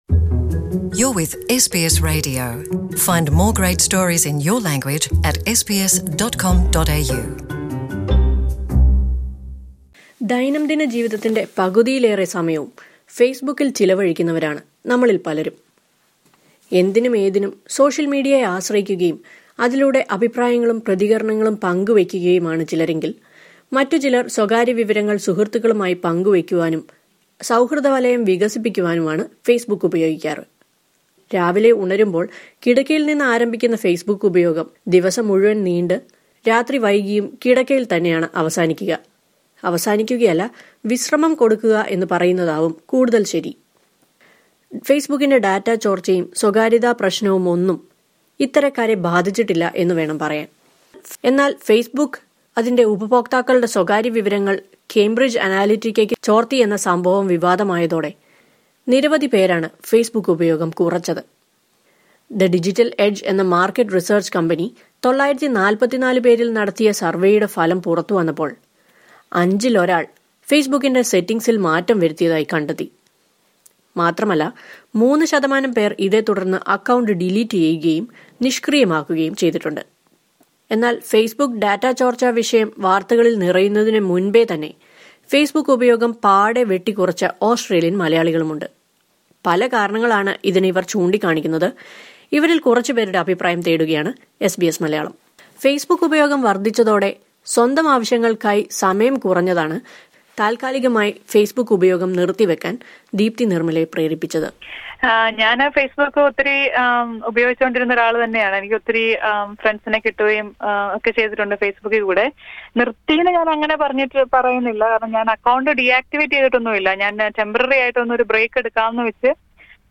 At a time when people are under the influence of social media, listen to a few Australian Malayalees who deleted and deactivated their Facebook accounts.